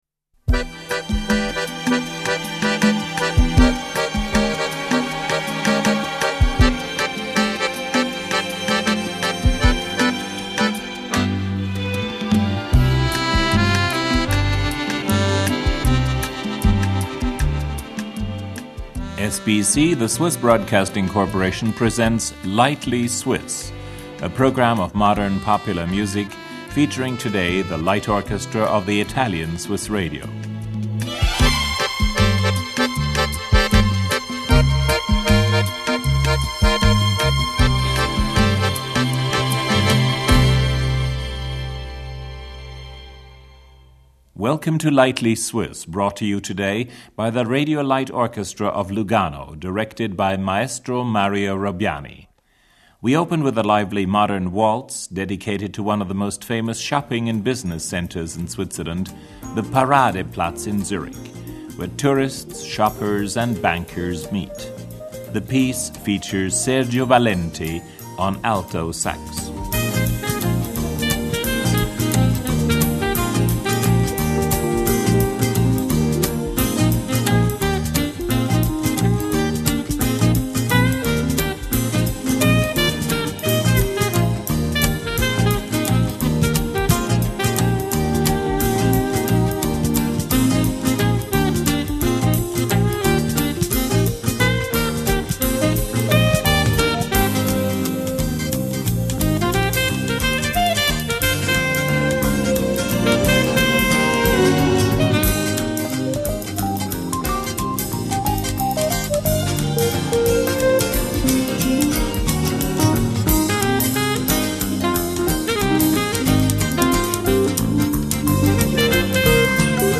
alto sax.
piano